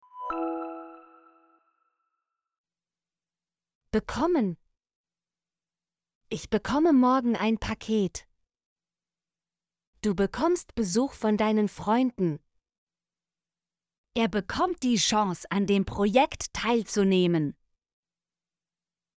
bekommen - Example Sentences & Pronunciation, German Frequency List